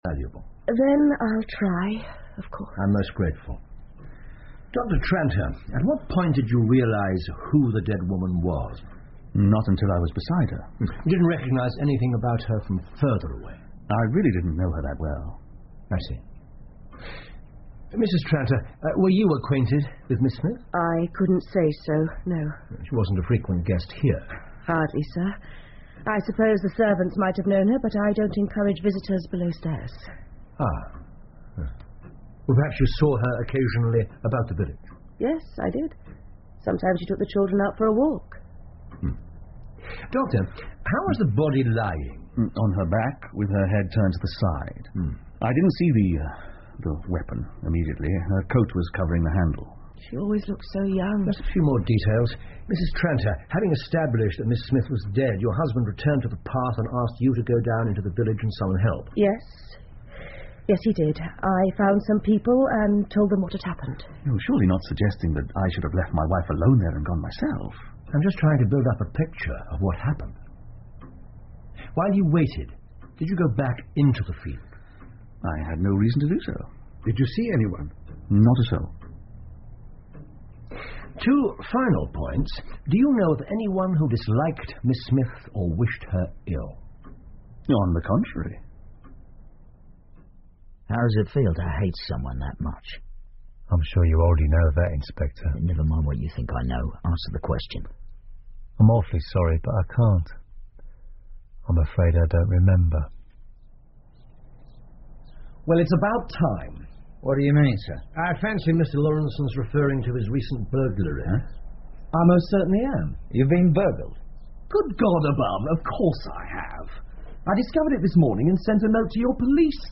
福尔摩斯广播剧 The Shameful Betrayal Of Miss Emily Smith 4 听力文件下载—在线英语听力室